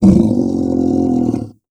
MONSTER_Growl_Medium_16_mono.wav